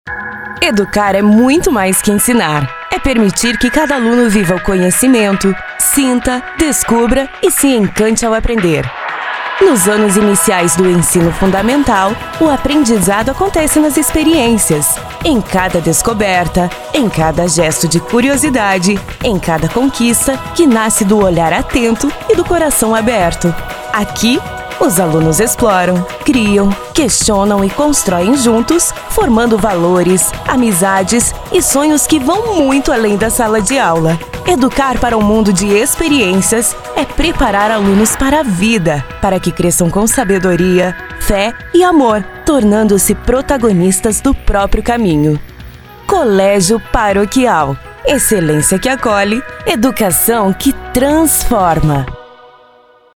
INSTITUCIONAL :
Impacto
Animada